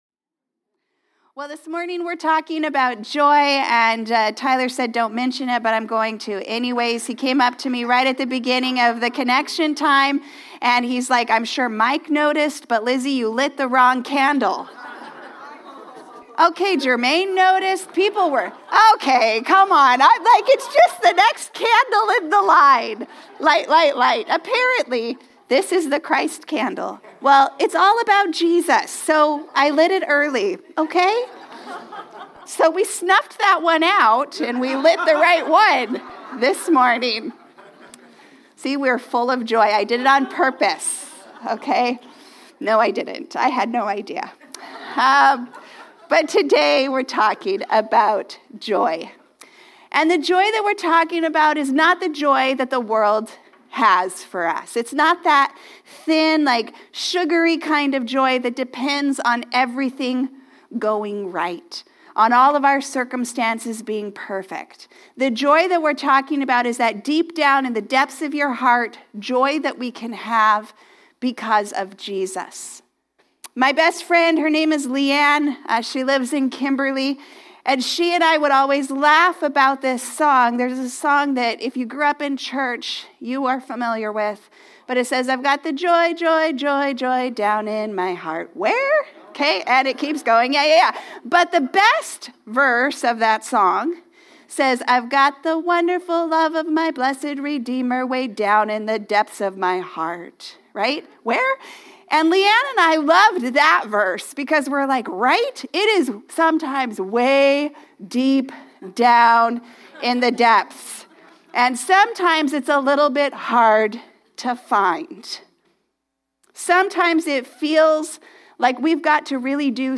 This Sunday’s sermon explores the Advent theme of joy; not the fragile, surface-level joy the world offers, but the deep, unshakeable joy rooted in Christ. Through Mary in the chaos of the nativity and Paul in the confinement of a Roman prison, the Bible shows that true joy is not born from ideal circumstances but from the presence of Jesus.